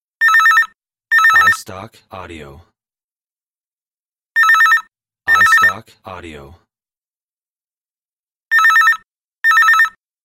Phone Ring #2
Digitally recorded of desktop phone ring sound.